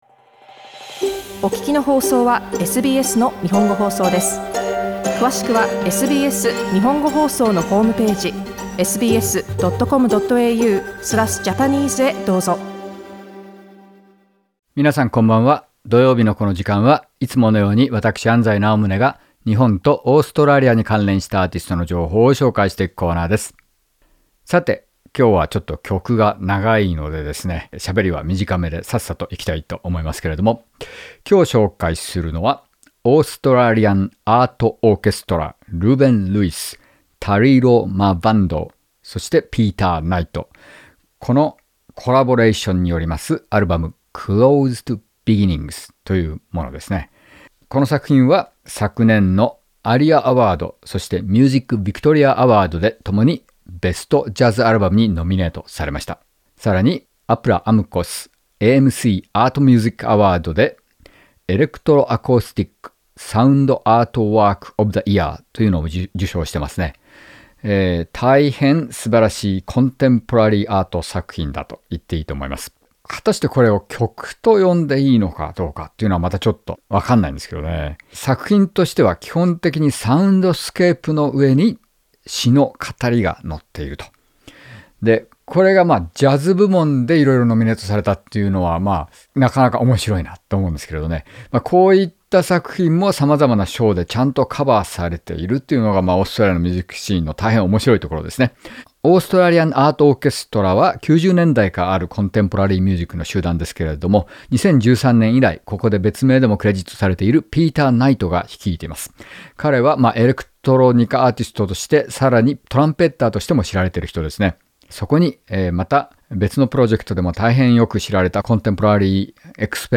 spoken word artist